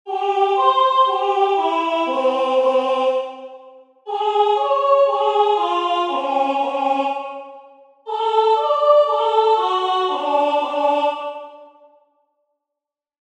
- coa formación de arpexio en distintas notas, subindo e baixando cromáticamente, farémolo con sílabas como na, ma, ni, pi,...
Arpexio ascendente cromaticamente
arpegio_descendente_voz.mp3